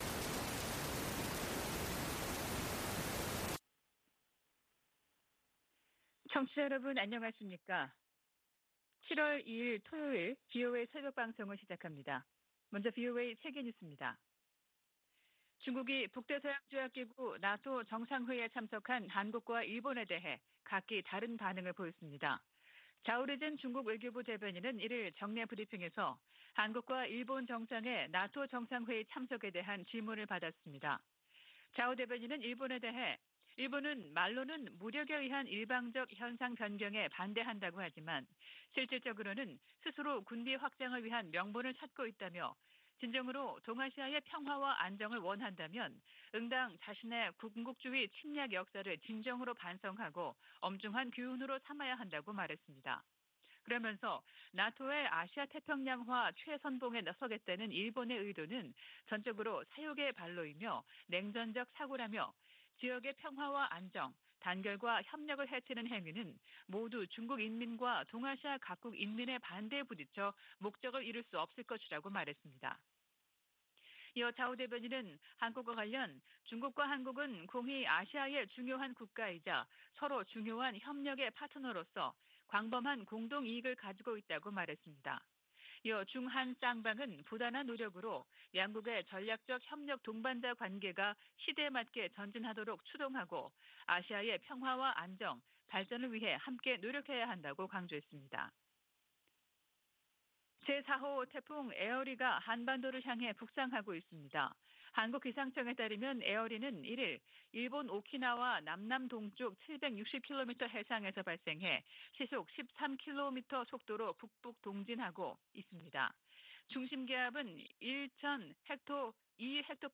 VOA 한국어 '출발 뉴스 쇼', 2022년 7월 2일 방송입니다. 북대서양조약기구(NATO·나토) 정상회의가 막을 내린 가운데 조 바이든 미국 대통령은 ‘역사적’이라고 평가했습니다. 미국의 한반도 전문가들은 윤석열 한국 대통령이 나토 정상회의에서 북핵 문제에 대한 미한일 3각협력 복원 의지를 분명히했다고 평가했습니다. 미 국무부가 미일 동맹 현대화와 미한일 삼각공조 강화 등 일본 전략을 공개했습니다.